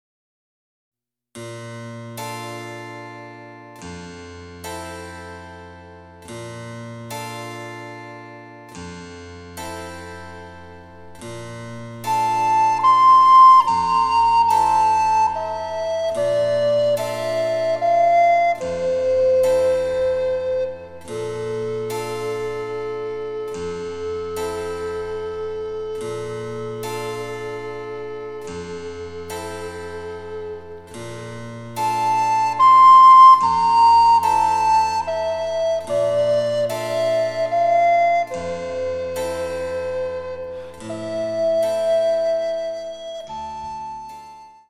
デジタルサンプリング音源使用